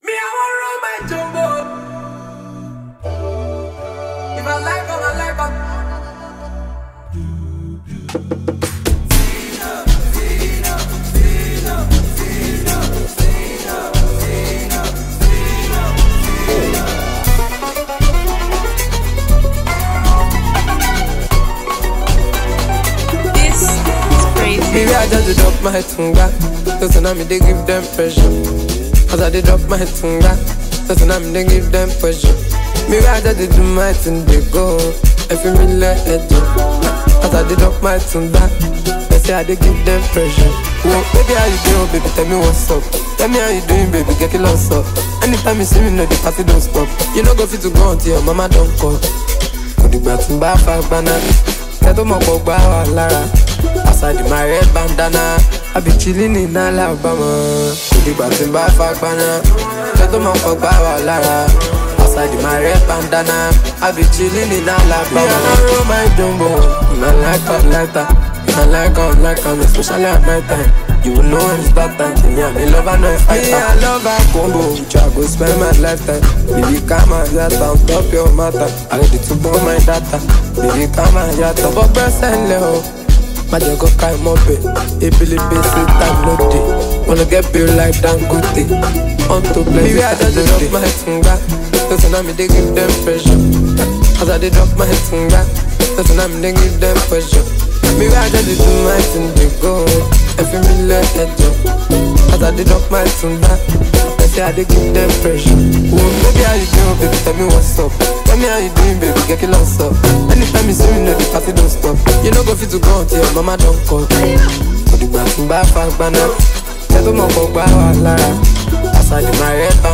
Nigerian Afrobeats
a record that blends raw energy, infectious rhythms